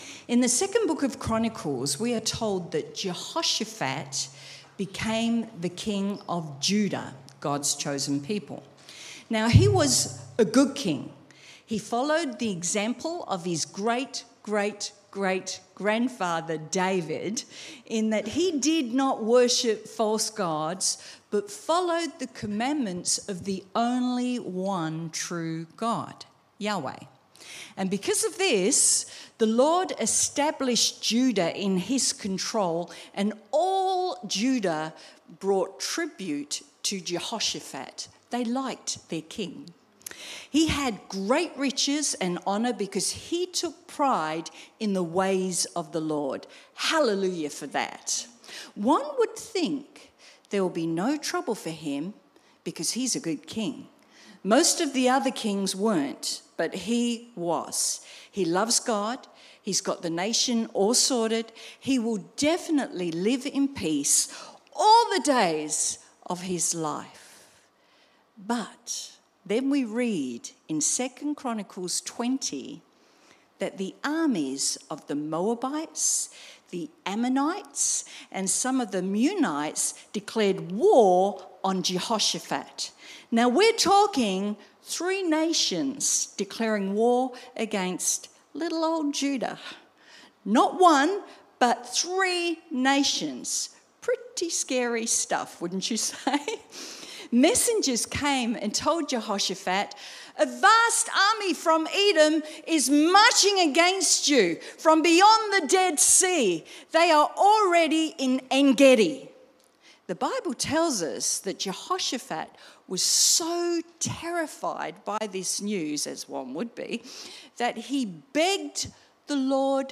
Sermon Transcript What’s the Point of Christians Praising God?